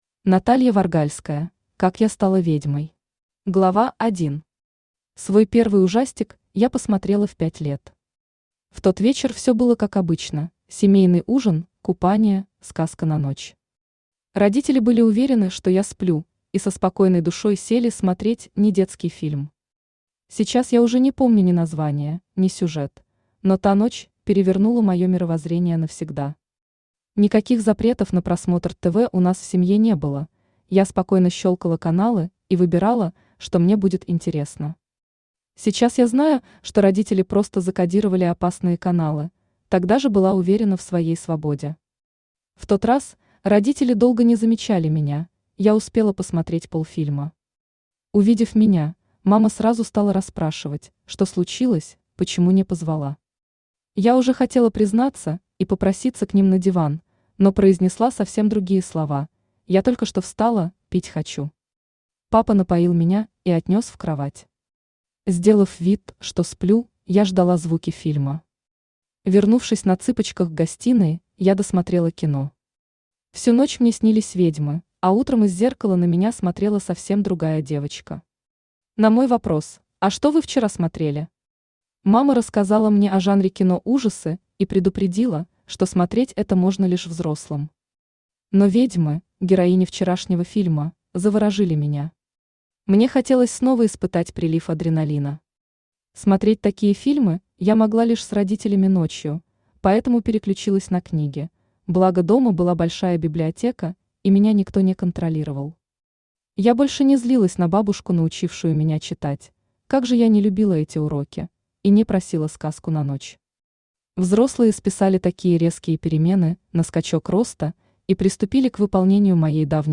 Аудиокнига Как я стала ведьмой | Библиотека аудиокниг
Aудиокнига Как я стала ведьмой Автор Наталья Варгальская Читает аудиокнигу Авточтец ЛитРес.